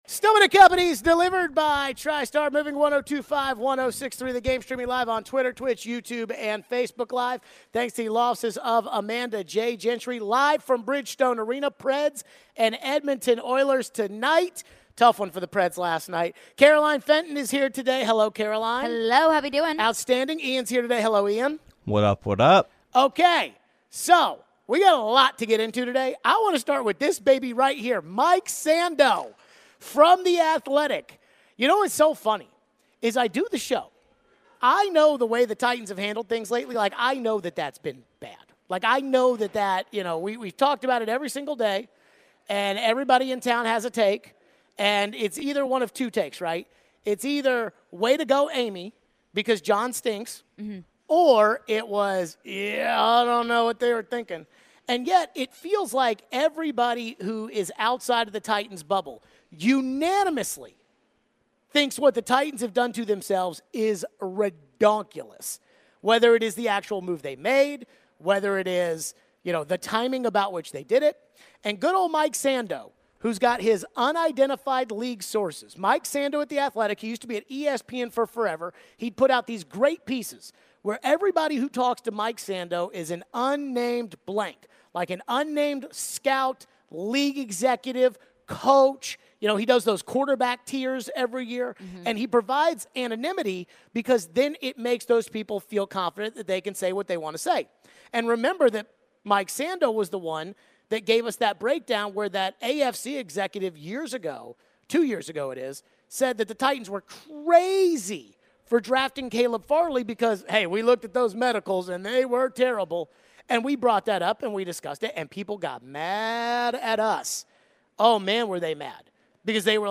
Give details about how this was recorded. Mike Vrabel said he won't make any coaching changes in the middle of the season. We take your phones.